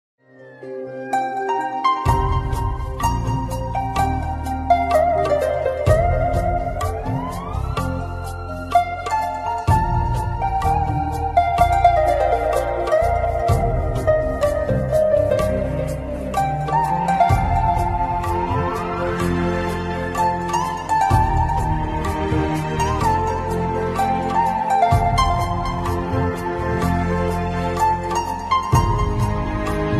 Hindi Bollywood Category